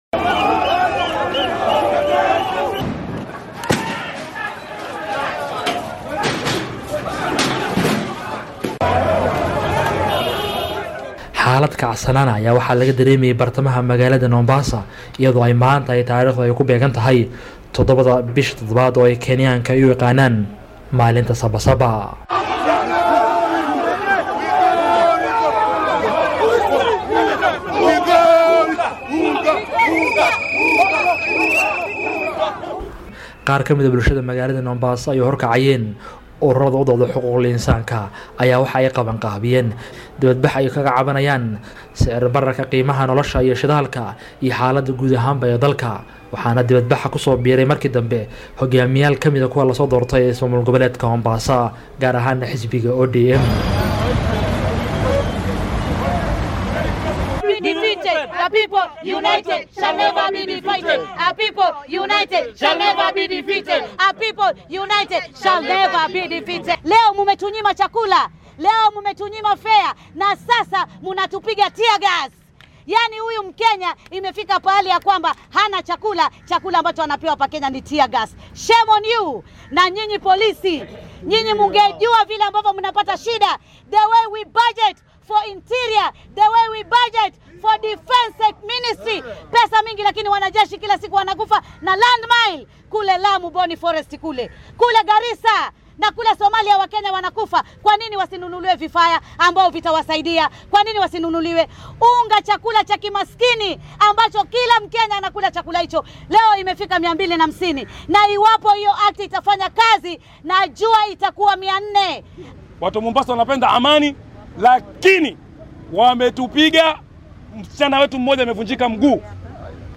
Meelaha maanta ciidamada booliiska ay dibadbaxayaasha ku kala eryeen waxaa ka mid ah ismaamulka Mombasa ee gobolka Xeebta sida uu warbixintan ku eegaya